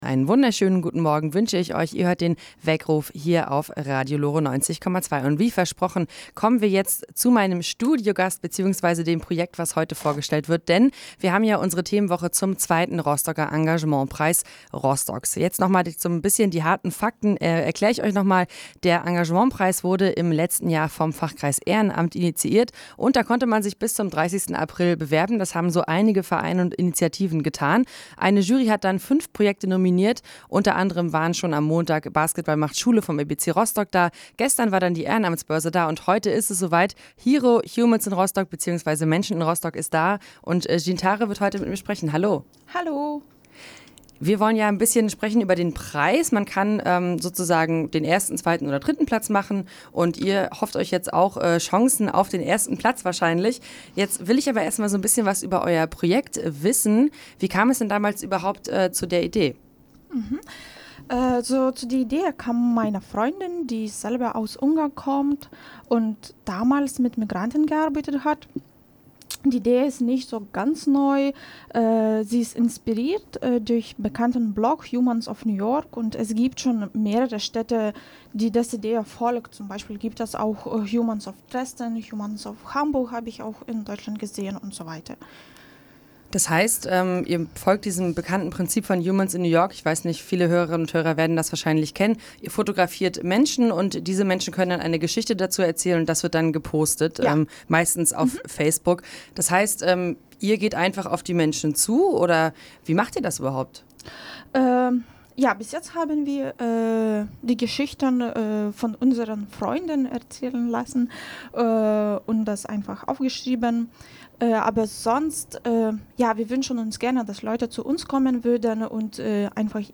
In der Woche ab dem 23.07. haben wir jeden Tag ein Projekt zu uns ins Studio eingeladen, damit sie uns ihr Vorhaben präsentieren: